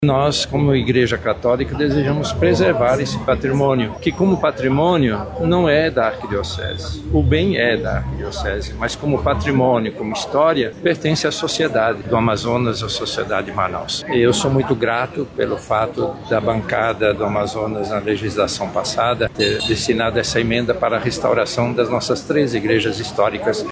O Arcebispo de Manaus, Cardeal Leonardo Steiner, destaca a reforma como um cuidado para com a história da cidade e da fé do povo.